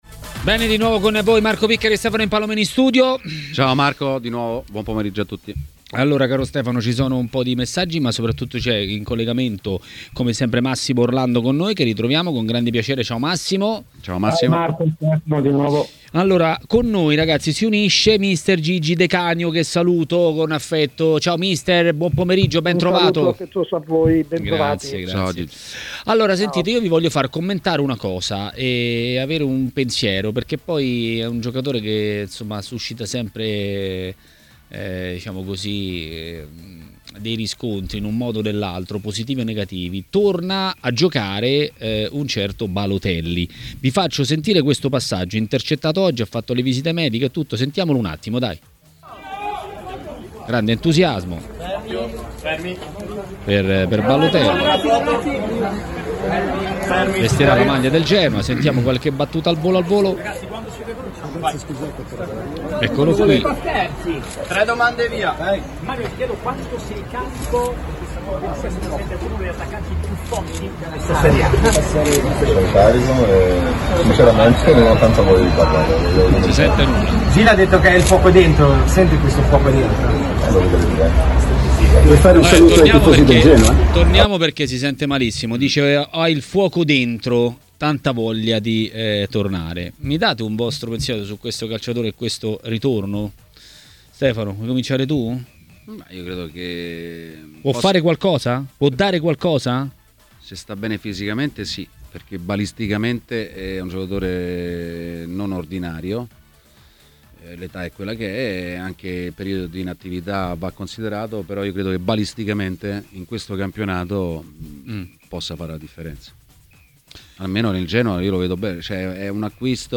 A Maracanà, nel pomeriggio di TMW Radio, è il momento dell'ex calciatore Massimo Orlando.